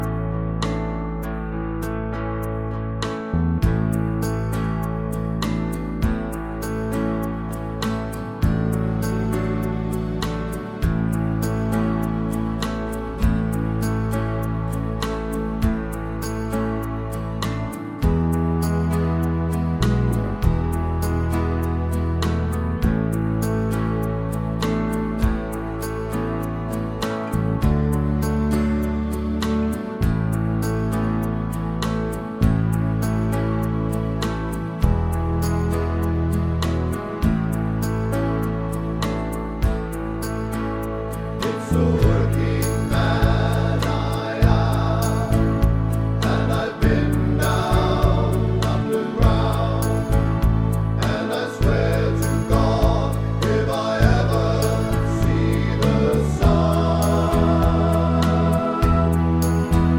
no Backing Vocals Crooners 4:15 Buy £1.50